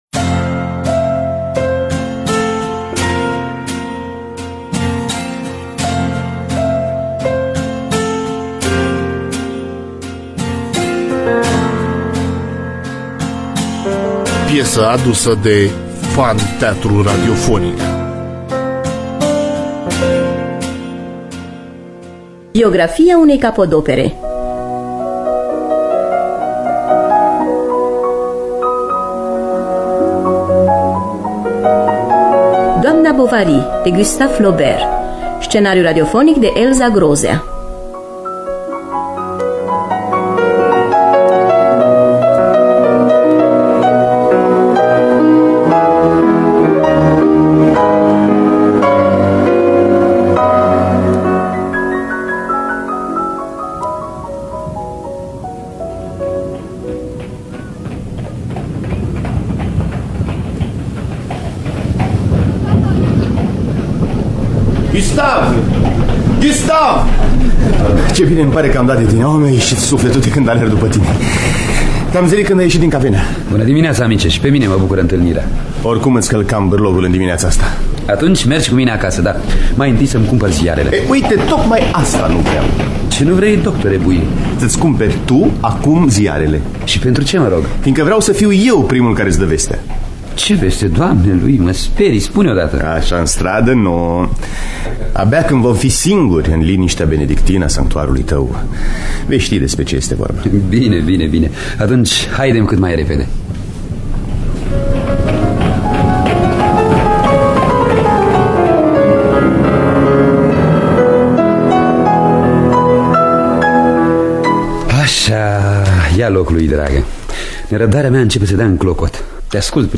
Biografii, Memorii: Gustave Flaubert – Doamna Bovary (1977) – Teatru Radiofonic Online